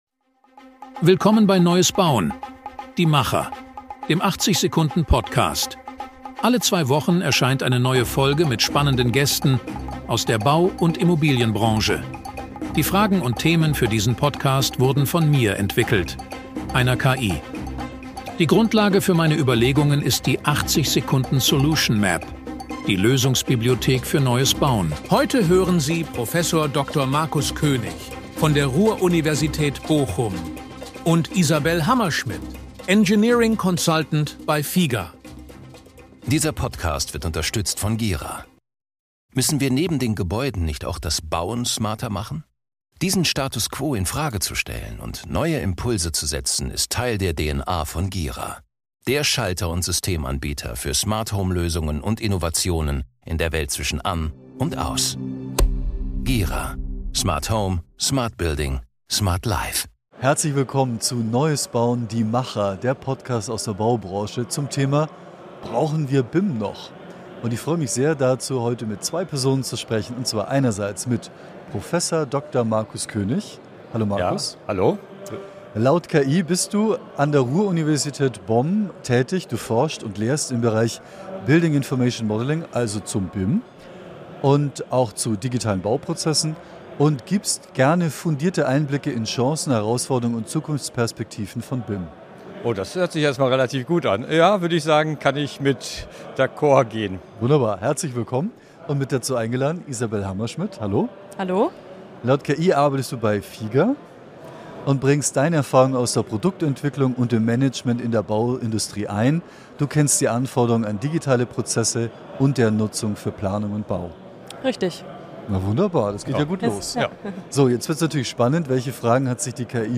Und um die großen Fragen: Industrialisierung, automatisiertes Bauen, KI, Nachhaltigkeit und Ökofinanzierung – alles ohne gute Daten unmöglich. Ein lebendiges Gespräch über Realismus statt Buzzwords, Mut zum ersten Schritt und eine Branche, die sich gerade neu aufstellt.